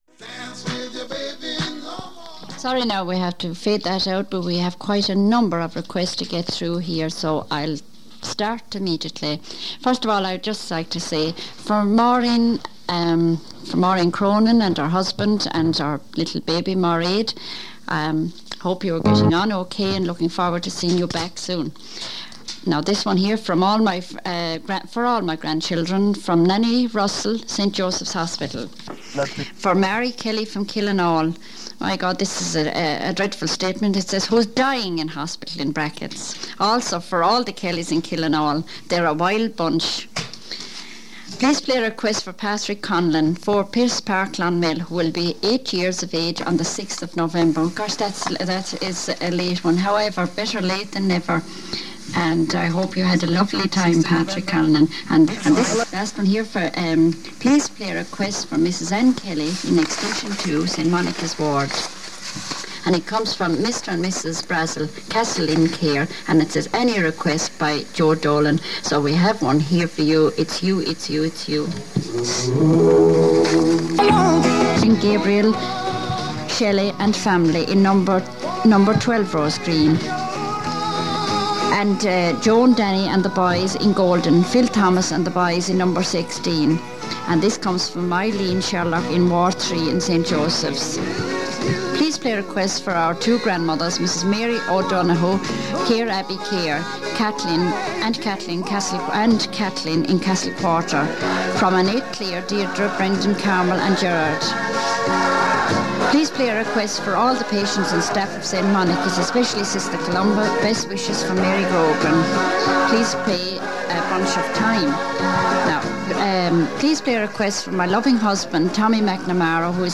Audio quality on the links is poor to fair at best and it sounds as if the first generation of mobile phone technology was used (Telecom Éireann had established the Eircell network the previous December).